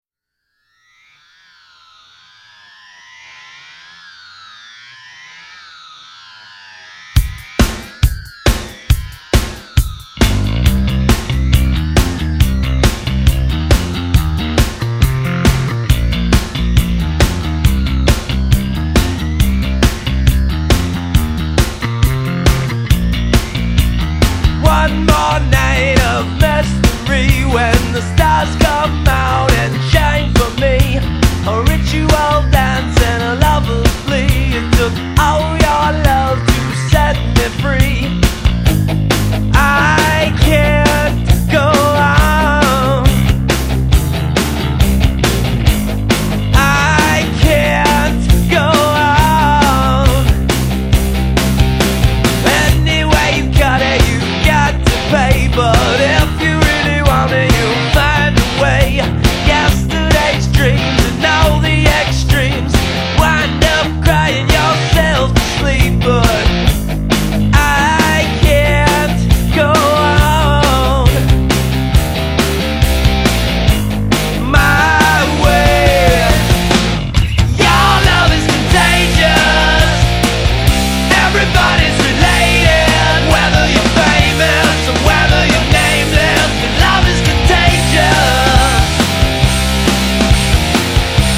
Bass, Vocals
Guitar, Vocals
Drums